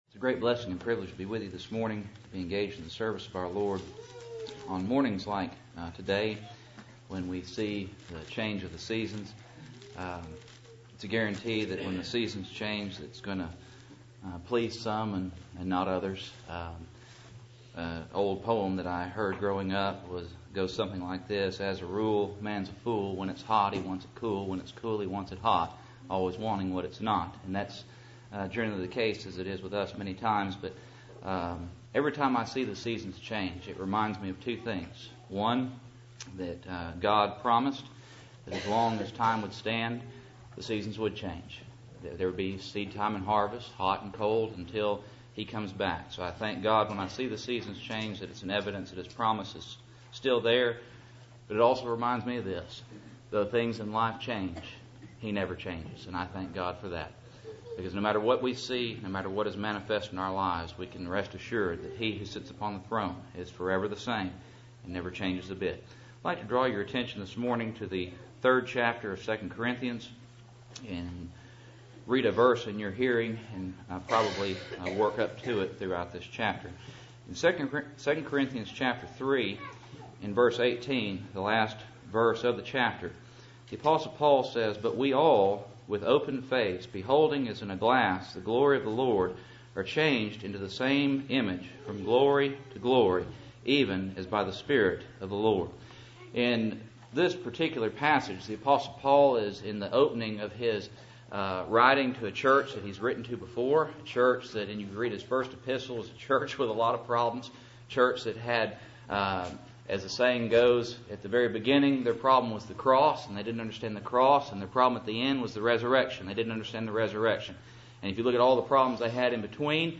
Passage: 2 Corinthians 3:18 Service Type: Cool Springs PBC Sunday Morning